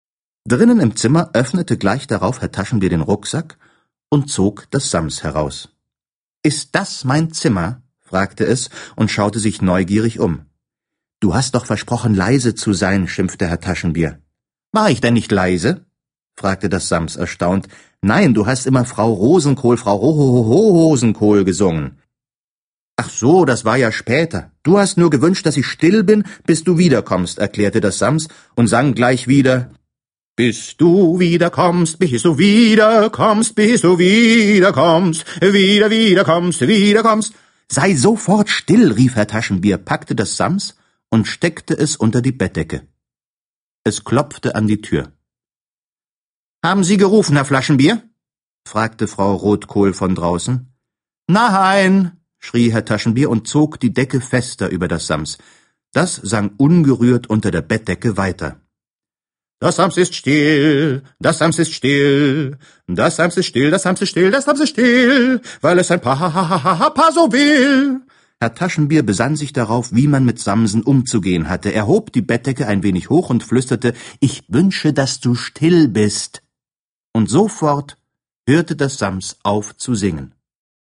Ulrich Noethen (Sprecher)
Das erste Buch vom Sams, erstmals als ungekürzte Lesung vom Sams-Papa Ulrich Noethen